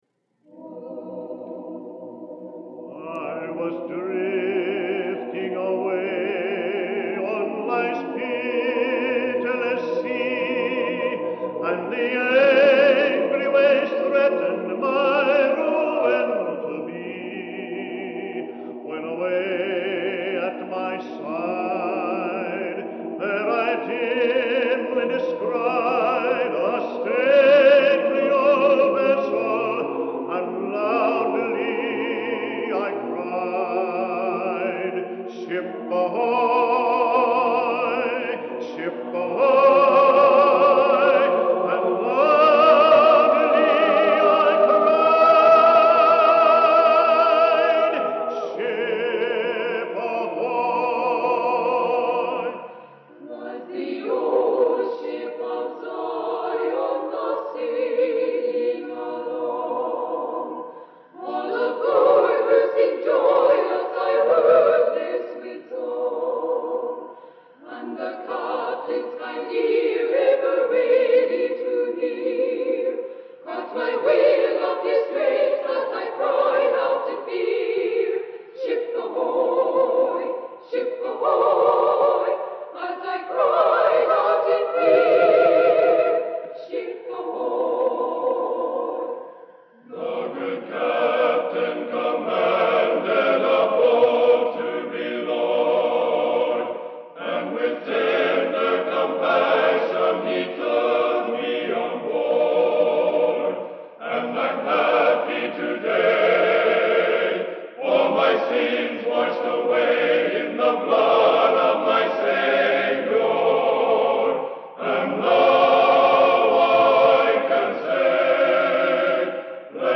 direct-to-disc recording